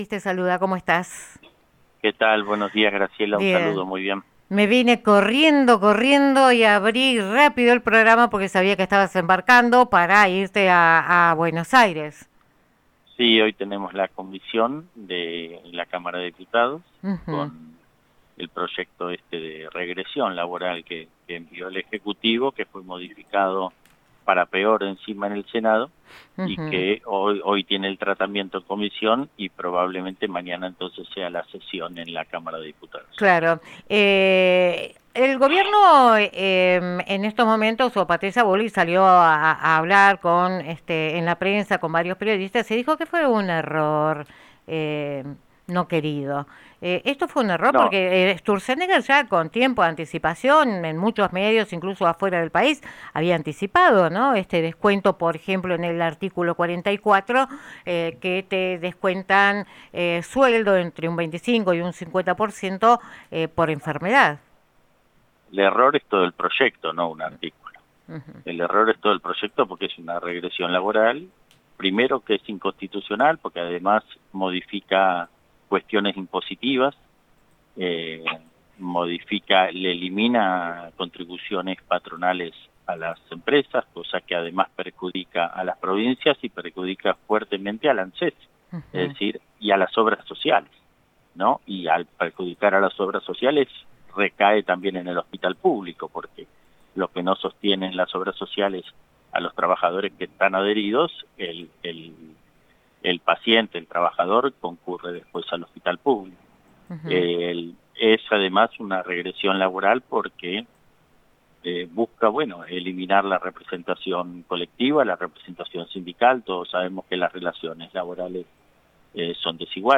Entrevista a Lorena Matzen, legisladora UCR. 03 de marzo 2026